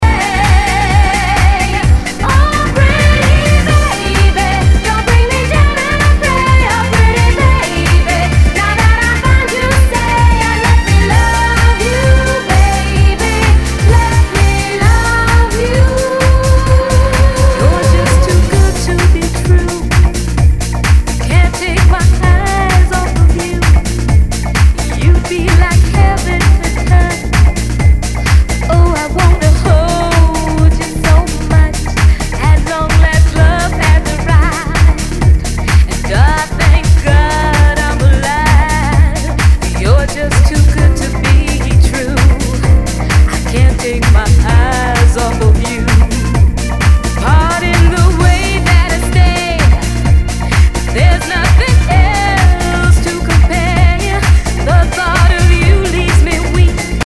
HOUSE/TECHNO/ELECTRO
ナイス！ディスコ・ハウス・ミックス！